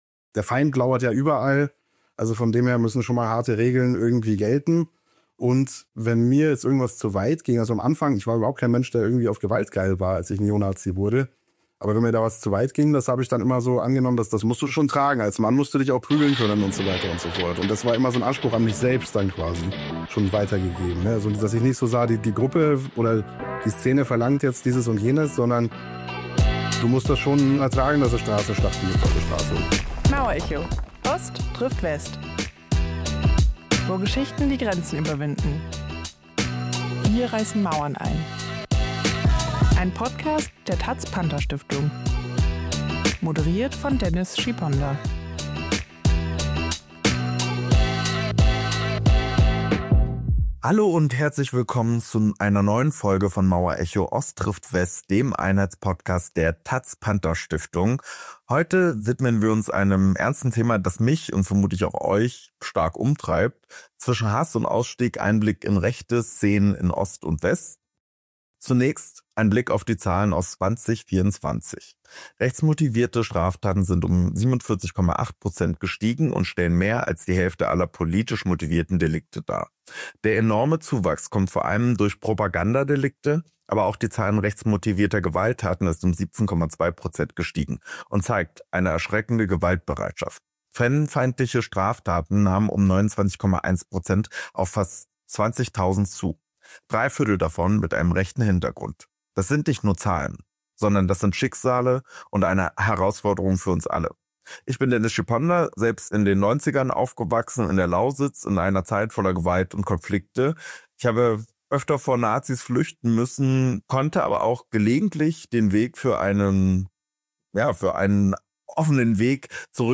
Im Gespräch geht es um die Fragen: Was treibt junge Menschen in die rechte Szene? Wie funktioniert der Ausstieg – und warum dauert er oft Jahre?